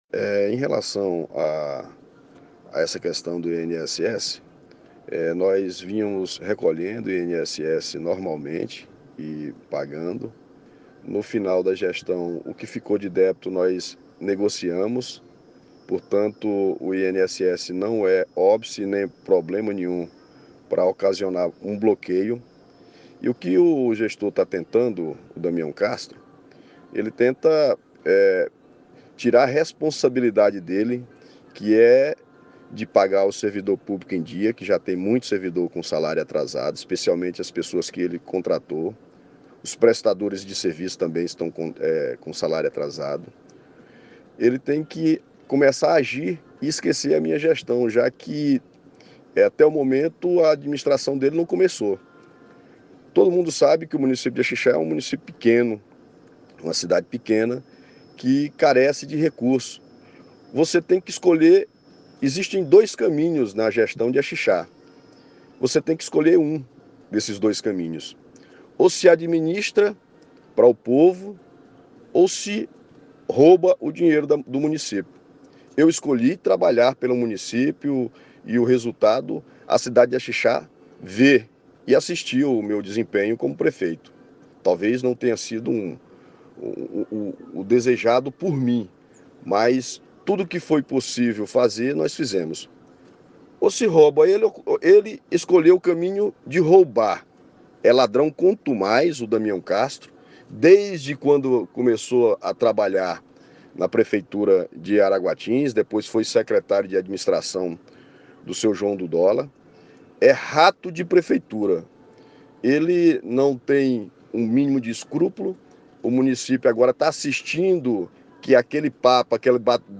Por telefone Auri manifestou indignação, escute o áudio da entrevista ao webjornal Folha do Bico: